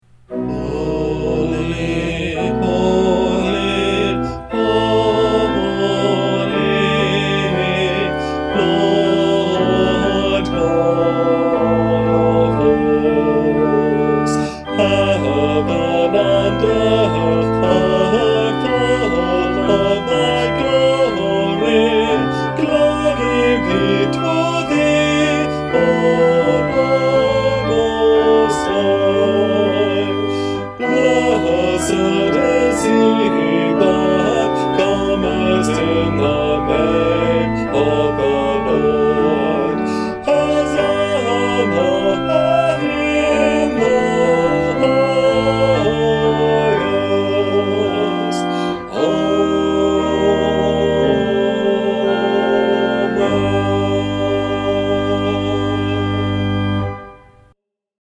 Congregational-Service-Sanctus_Benedictus.mp3